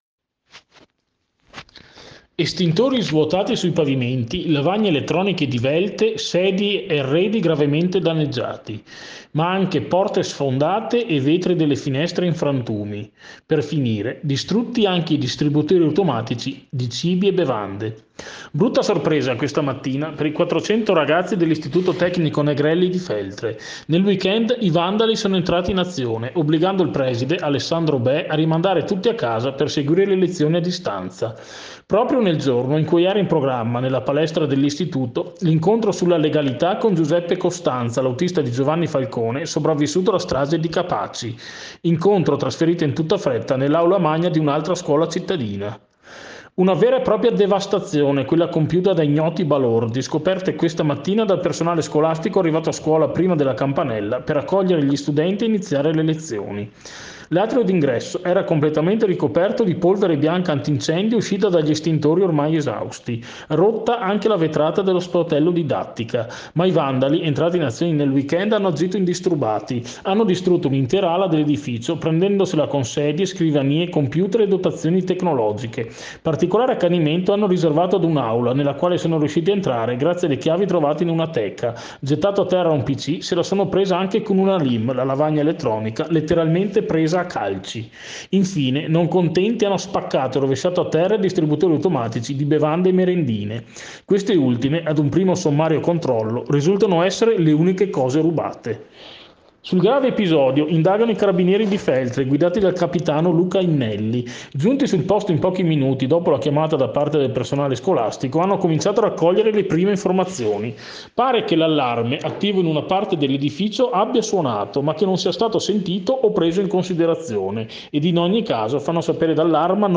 dal giornale radio piu’ DI IERI